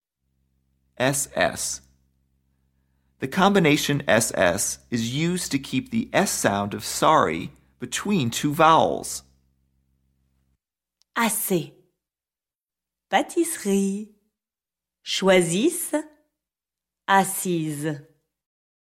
ss – The combination “ss” is used to keep the “s” sound of “sorry” between two vowels.